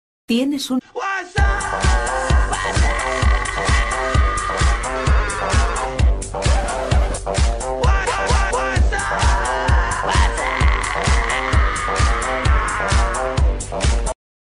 Efectos de sonido
tonos-jajaja_wapsaaap.mp3